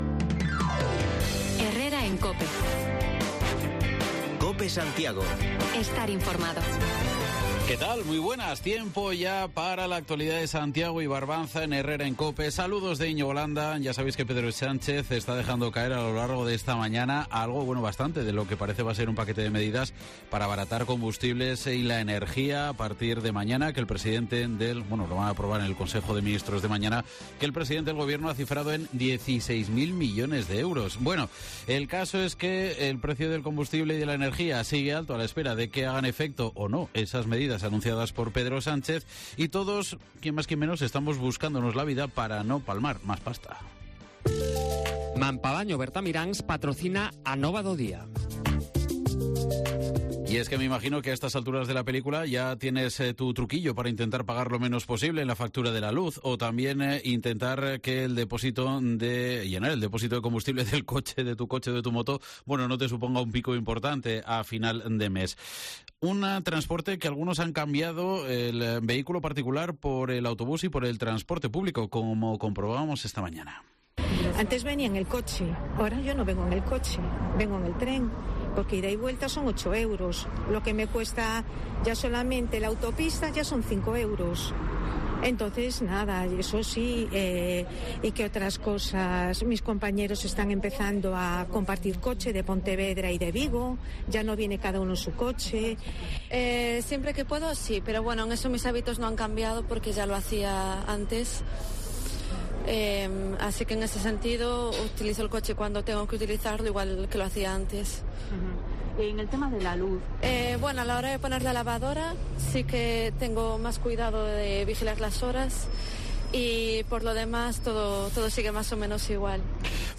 Bajamos los micrófonos de Cope a la calle para saber cómo intentamos capear el termporal del incremento de precios las economías domésticas: en la parada del bus metropolitano, cada vez son más personas las que reconocen que han decidido optar por el transporte público y dejar el coche en el garaje. La búsqueda de las "horas valle" para poner los electrodomésticos es también el pan de cada día